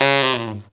Index of /90_sSampleCDs/Best Service ProSamples vol.25 - Pop & Funk Brass [AKAI] 1CD/Partition C/TENOR FX2